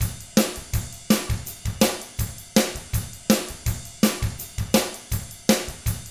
164ROCK T5-R.wav